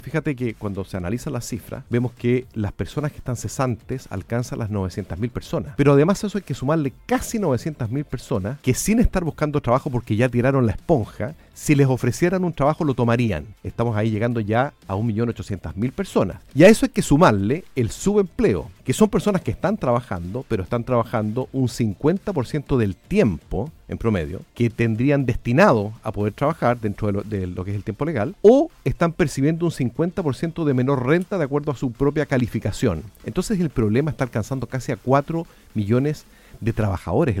En entrevista con La Radio, Palacios sostuvo que todo problema social tiene una raíz económica, por lo que —a su juicio— no se puede soslayar el impacto que la actual situación está teniendo en la ciudadanía.